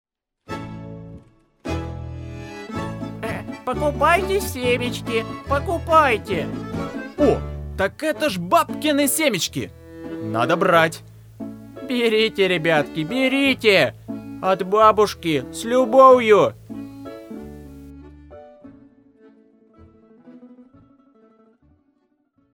Реклама "Бабкины семечки" Категория: Аудио/видео монтаж